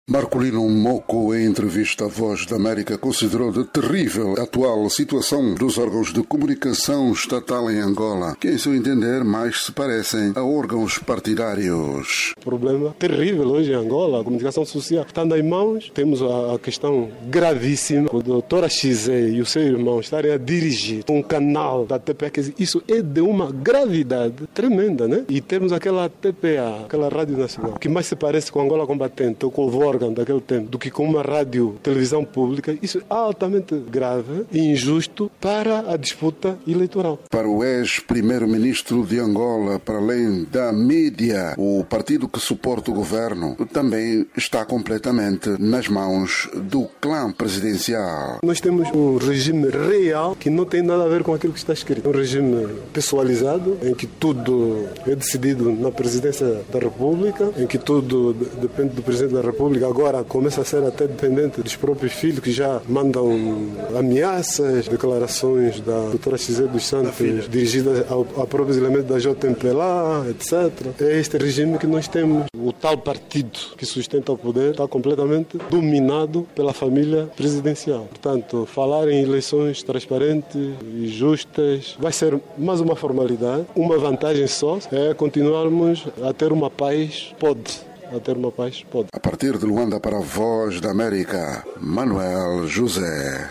Em conversa com a VOA, Moco diz que o facto de dois dos filhos do Presidente da República estarem a dirigir um canal da TPA é matéria suficiente para se ir a tribunal.